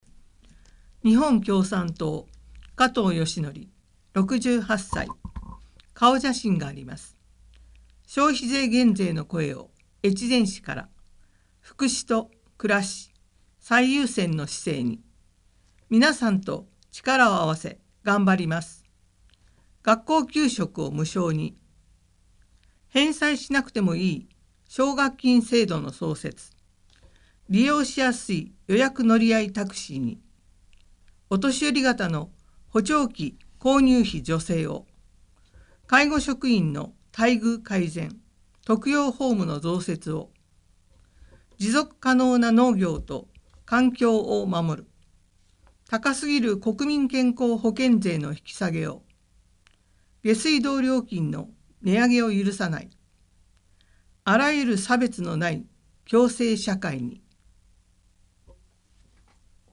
越前市議会議員補欠選挙の選挙公報（音訳）はこちら
（※選挙公報の音訳は音訳ボランティア「きくの会」の皆さんのご協力のもと配信しています。）